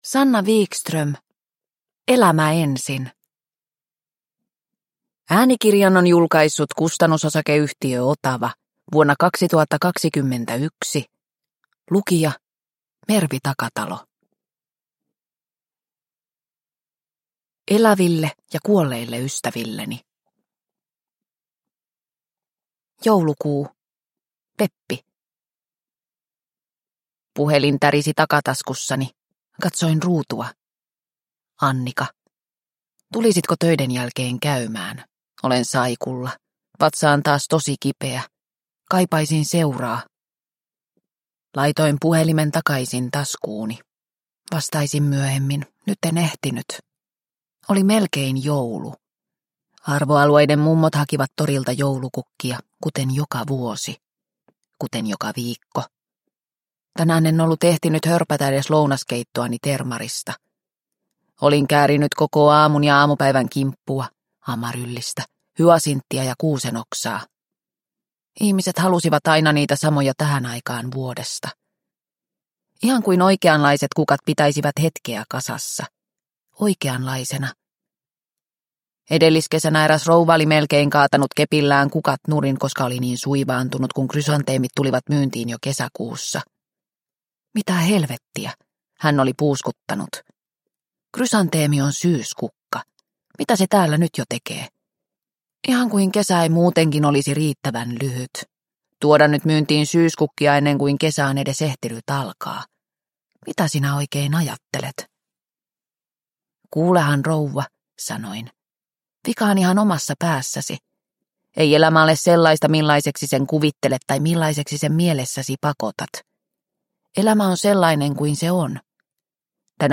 Elämä ensin – Ljudbok – Laddas ner